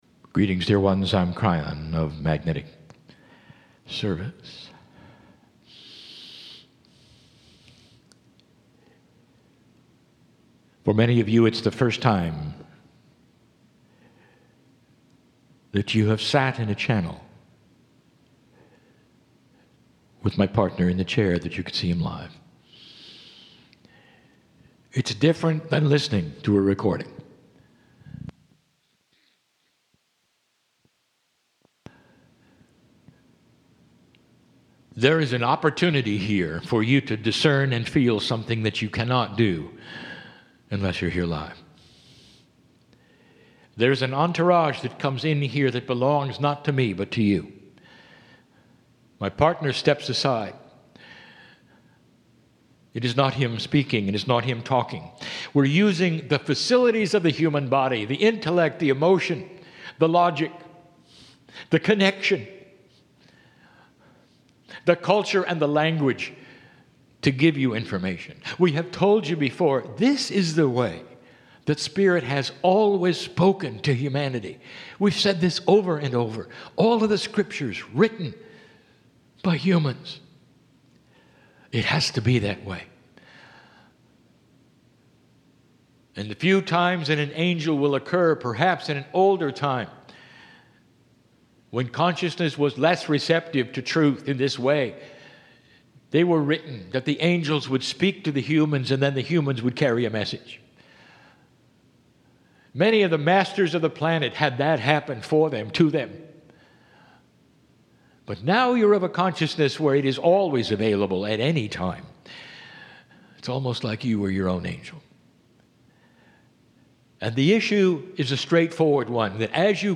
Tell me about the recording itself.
Live Kryon Channelling